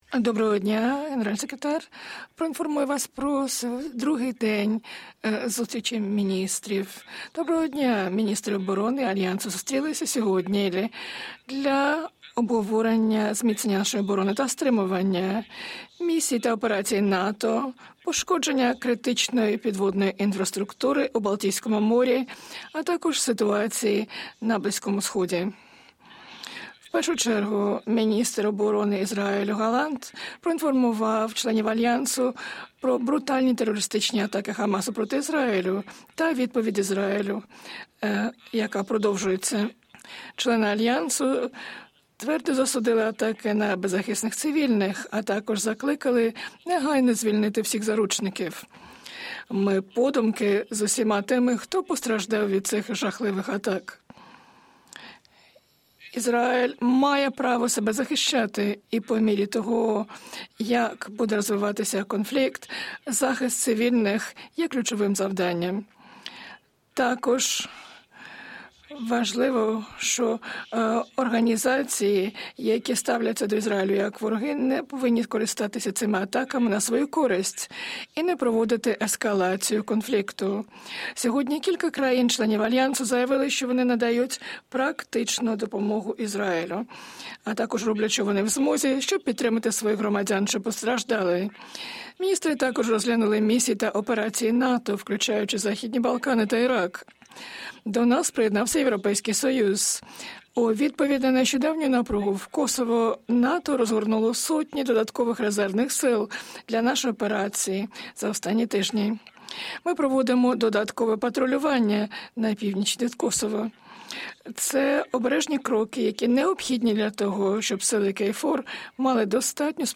Press conference
by NATO Secretary General Jens Stoltenberg following the meeting of NATO Ministers of Defence in Brussels